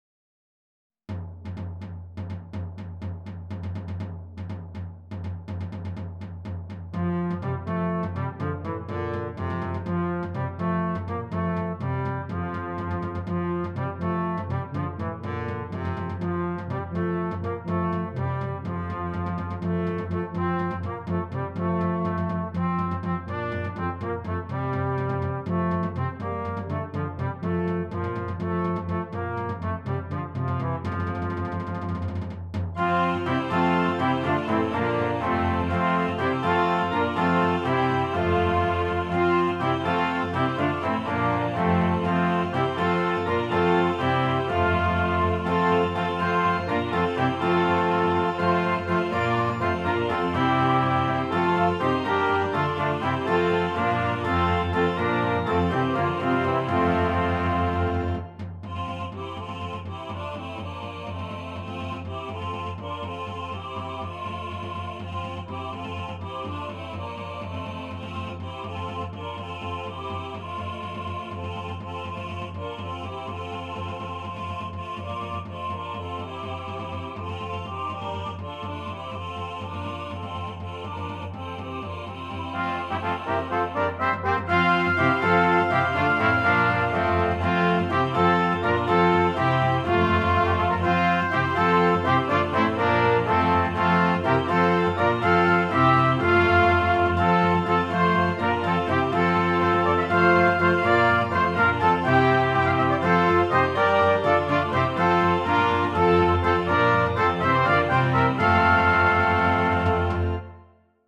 Brass Quintet, Organ and Optional Choir